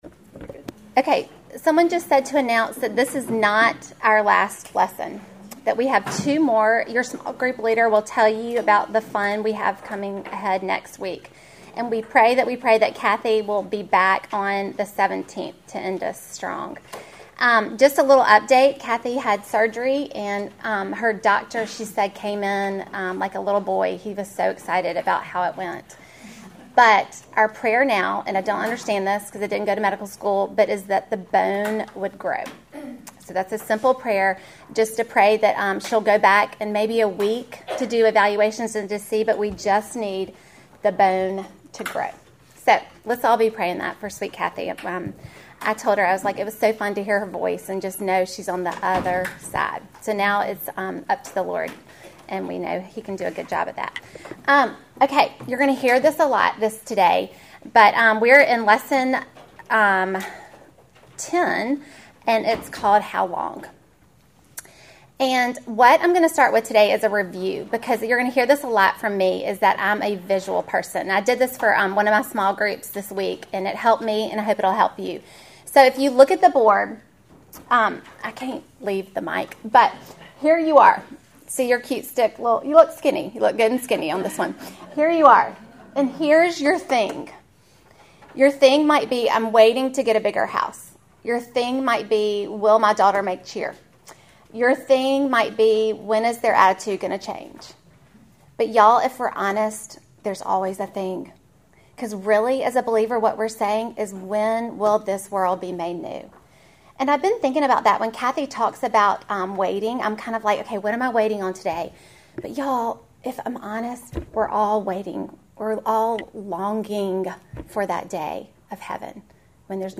Welcome to the fifteenth lesson in our series WAITING ON GOD!